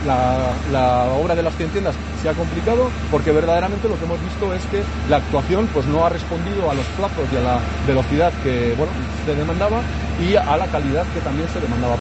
Pablo Hermoso de Mendoza, alcalde de Logroño: Las obras de las Cien Tiendas se han complicado